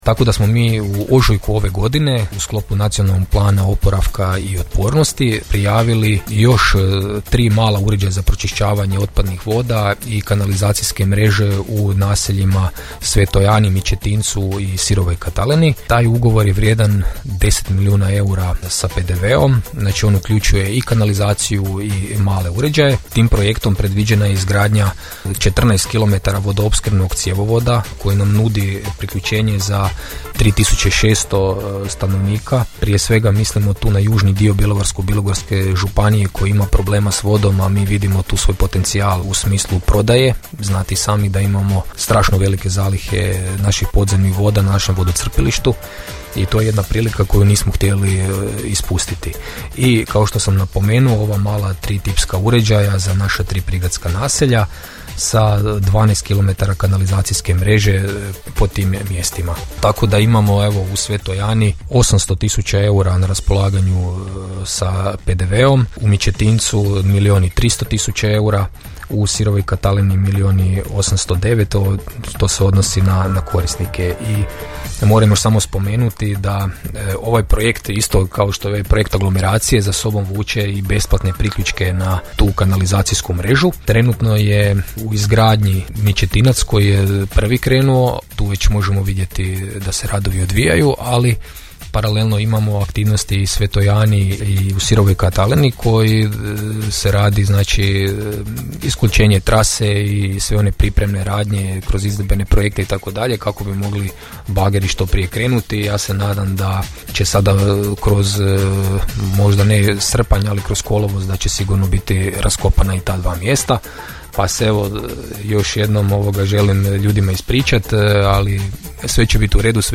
rekao je to u emisiji Gradske teme u programu Podravskog radija